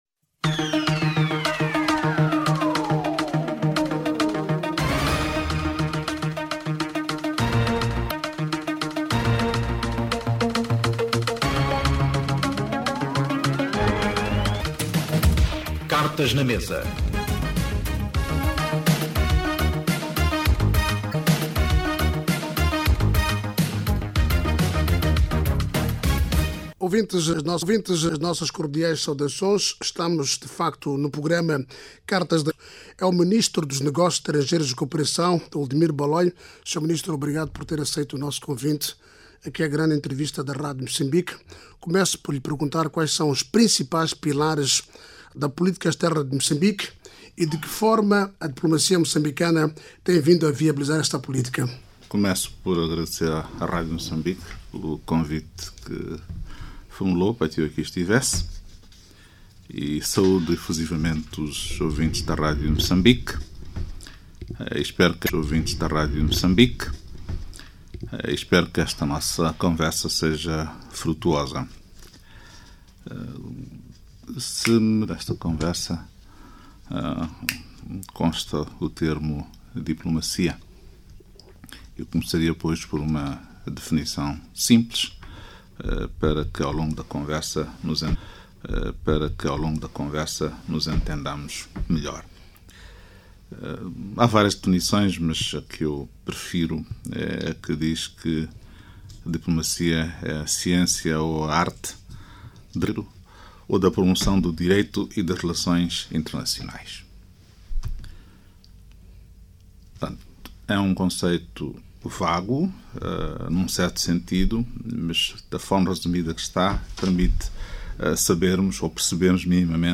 Ministro Baloi no programa Cartas na mesa da Radio Mocambique.mp3